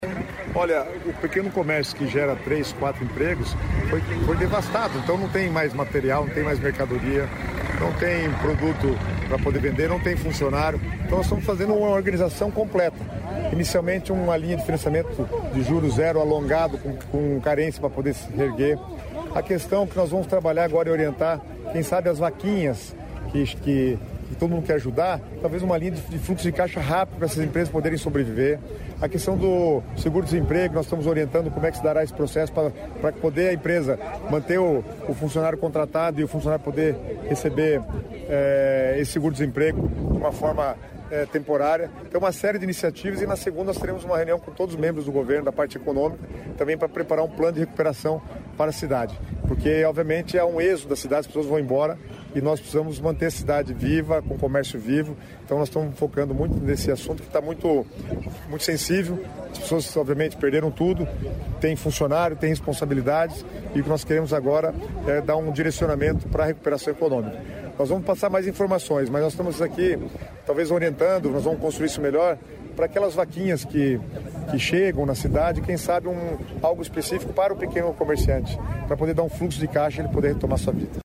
Sonora do secretário das Cidades, Guto Silva, sobre o auxílio para empregados e empregadores em Rio Bonito do Iguaçu